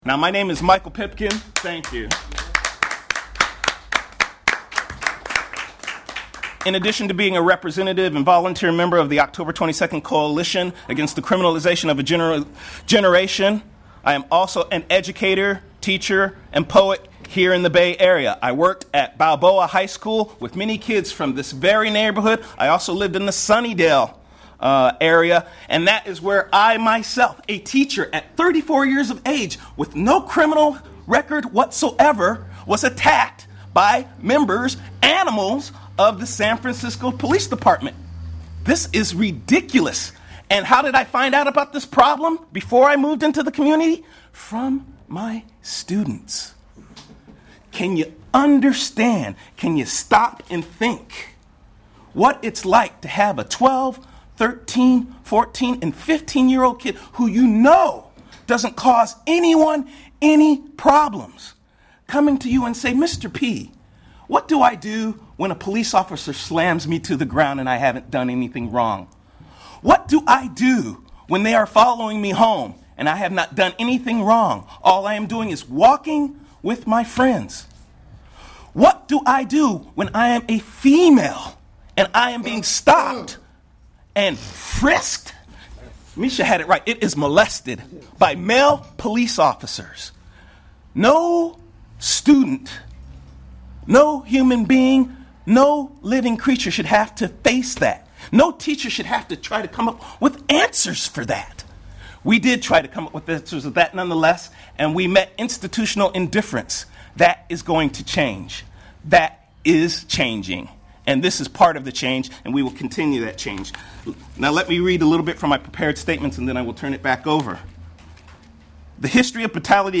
At a press conference in Hunters Point on Friday morning
Community members speaking at the press conference made links between individual instances of police brutality and a larger systemic problem within the police force.